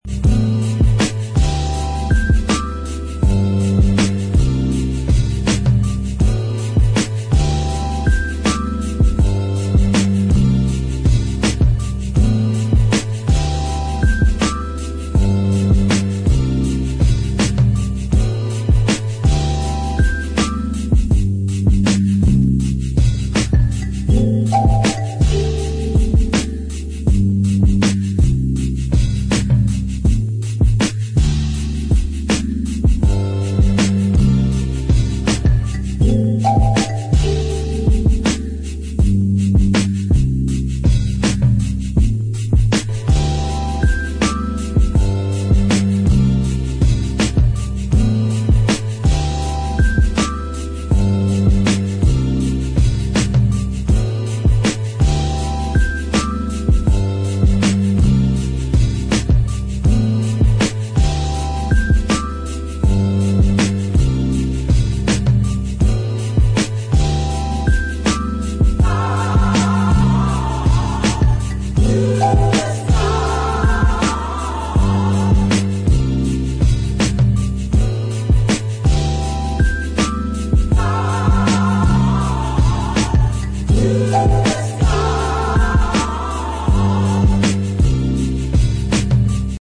Laidback and chill tracks that... more...